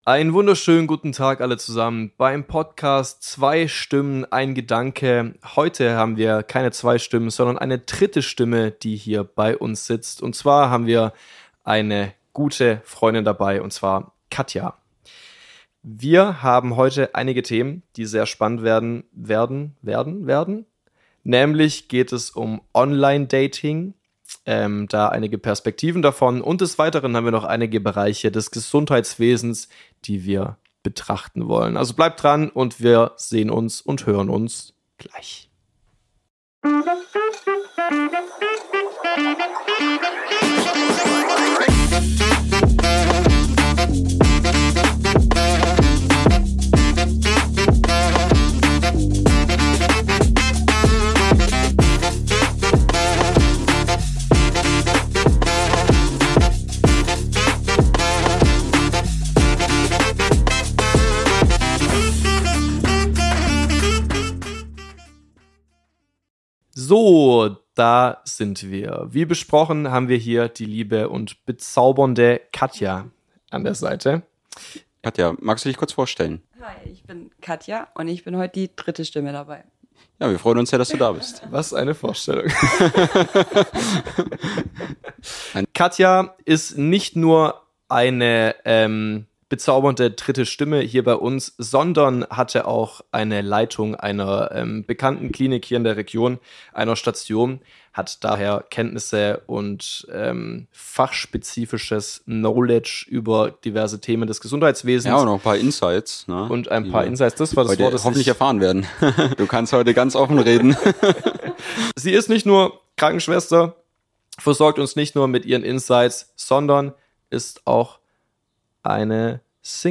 im heimischen Wohnzimmer